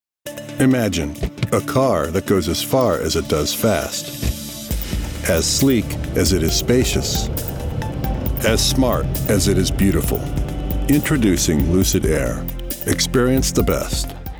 Commercial - Lucid Motors
USA English, midwest
Middle Aged
I work from a broadcast-quality home studio.